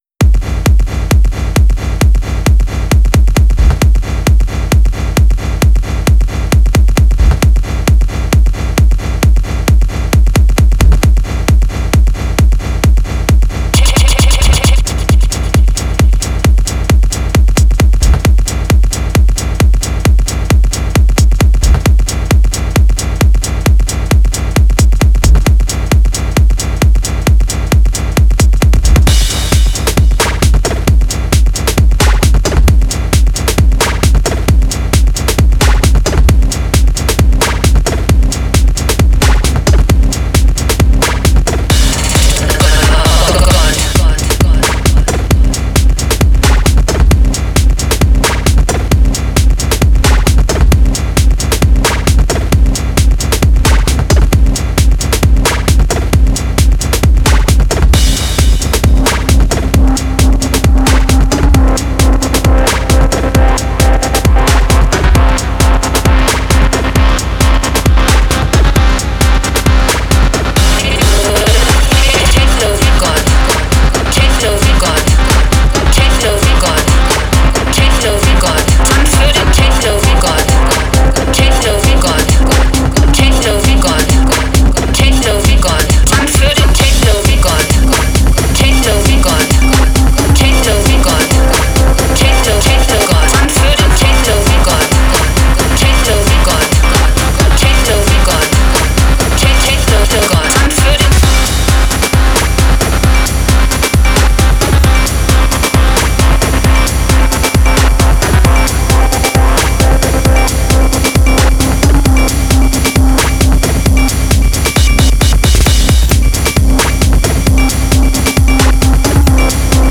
This track is an Amiga mod
vocals